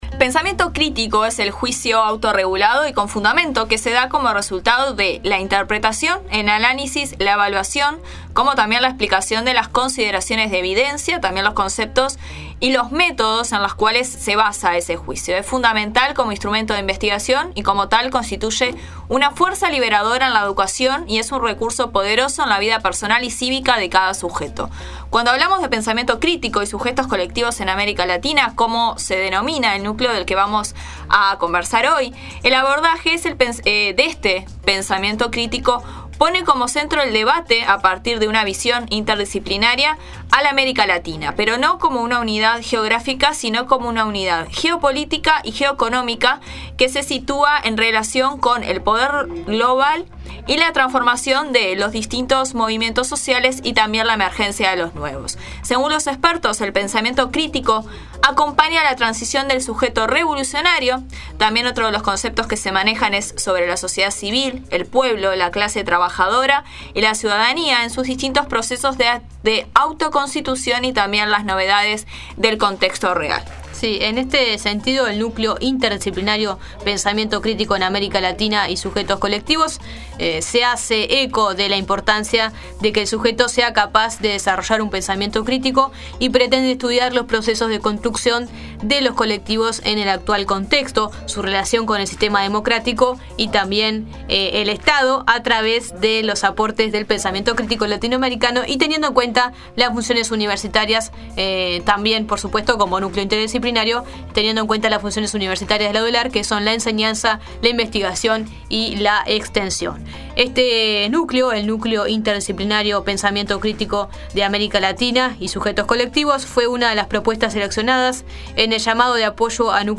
En este sentido, La Nueva Mañana dialogó